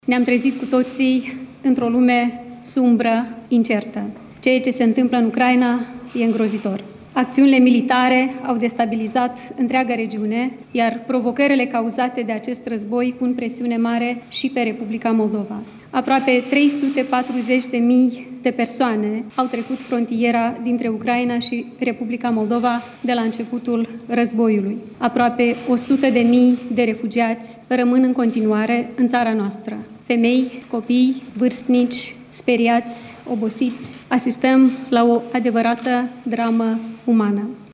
Peste 300.000 de ucraineni au intrat în Republica Moldova de la începutul conflictului din țara vecină, a spus președinta Maia Sandu: